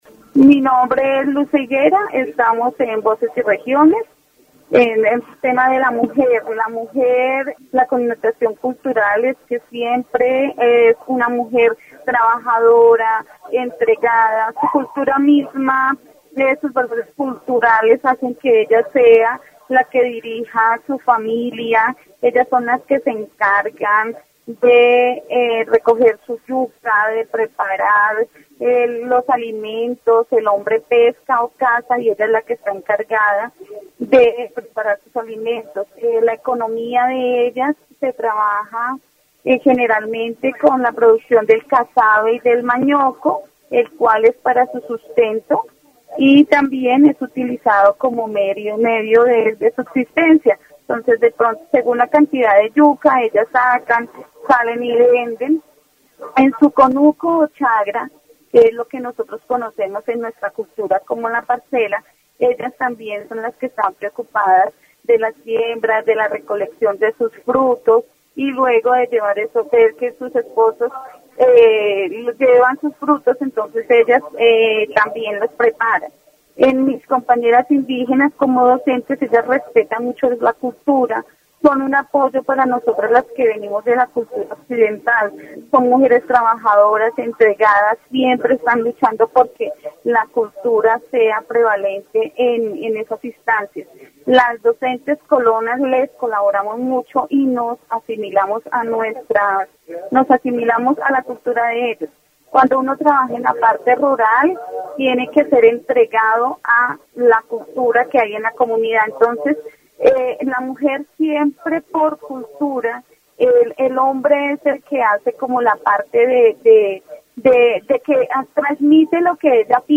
dc.subject.lembProgramas de radio